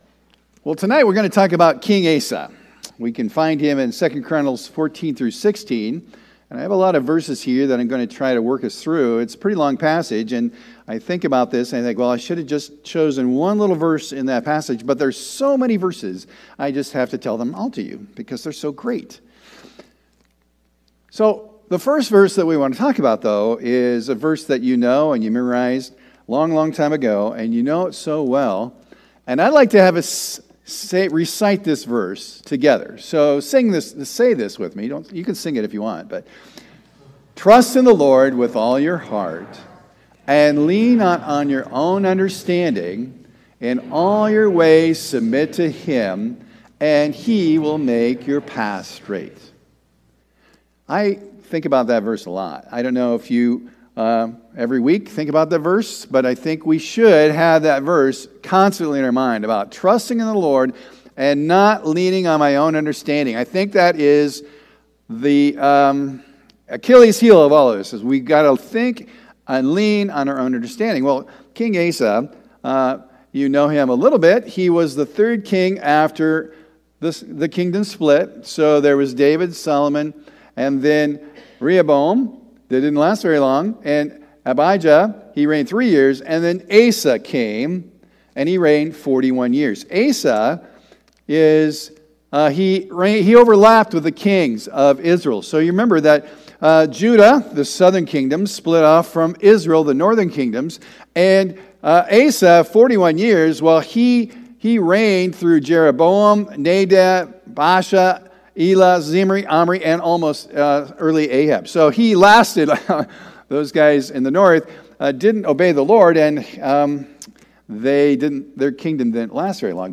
Sermon Recordings | Faith Community Christian Reformed Church